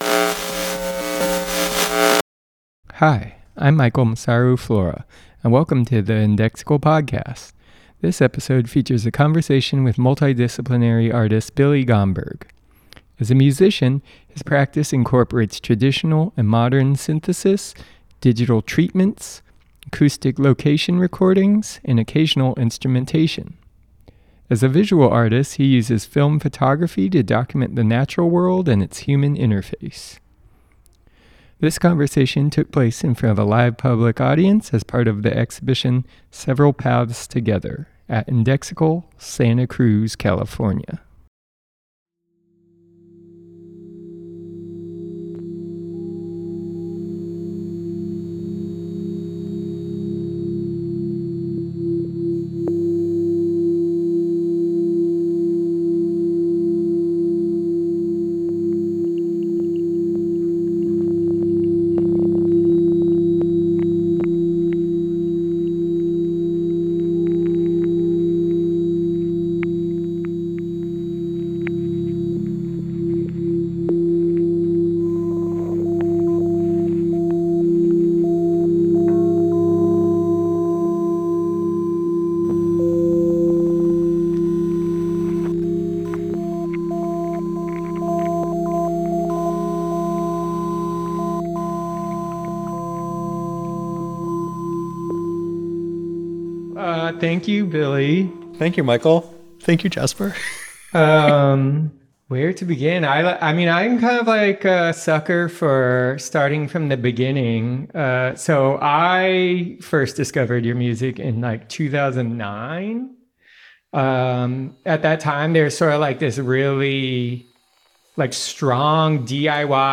The conversation took place following a live performance in support of their exhibition “Several Paths Together” at Indexical on June 14, 2025.